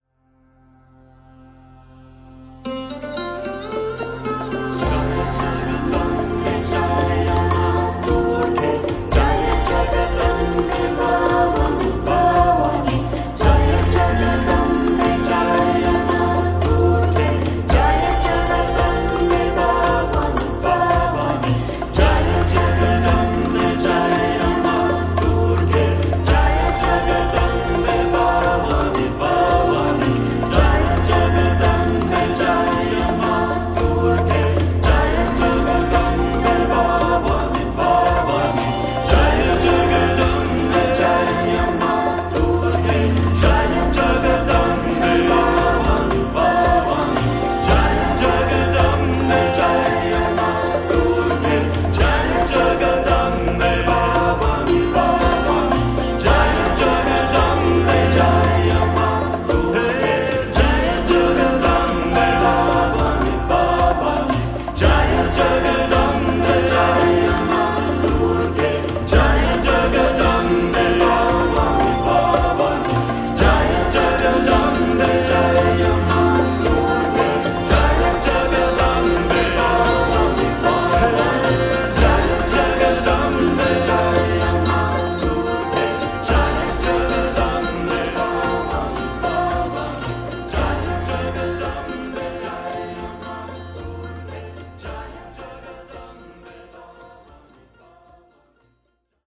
The call of song and audience response